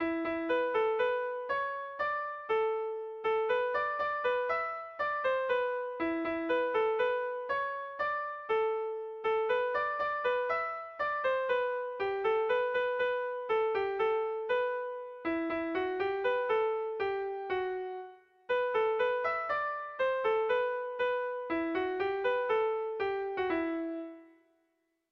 Melodías de bertsos - Ver ficha   Más información sobre esta sección
Irrizkoa
ABDE